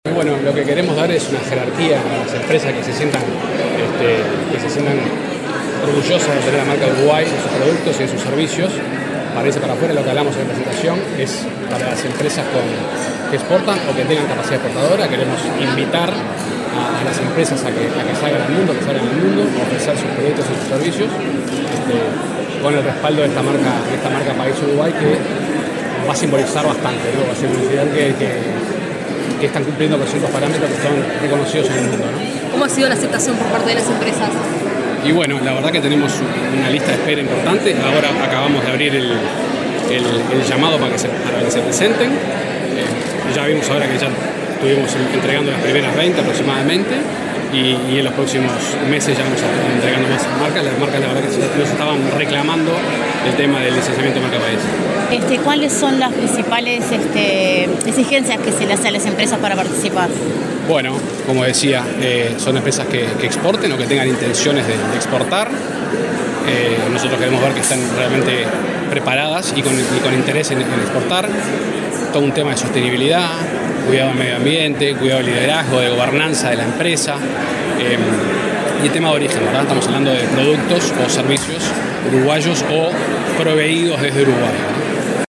Declaraciones del director ejecutivo de Uruguay XXI, Sebastián Risso
El director ejecutivo de Uruguay XXI, Sebastián Risso, dialogó con la prensa luego de la presentación del nuevo licenciamiento de la marca país